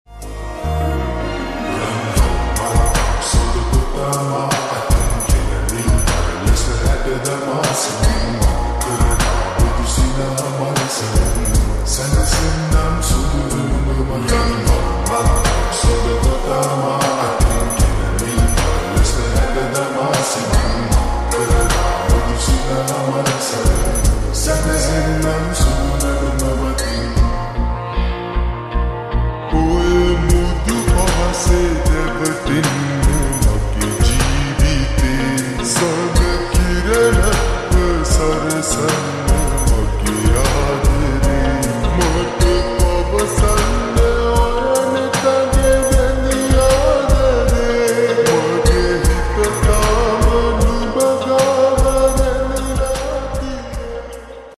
White Dragon 🐉 Sound 💣 boom